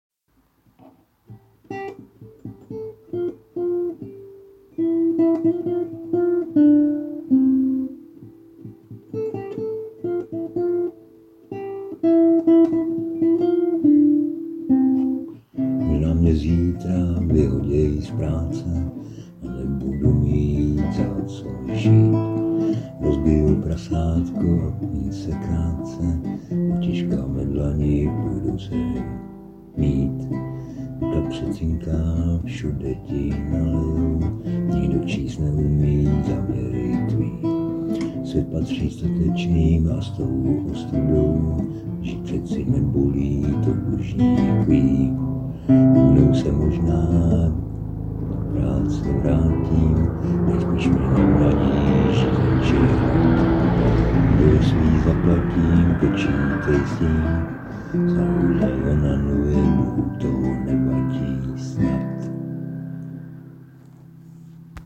originální nahrávka s helikoptérou!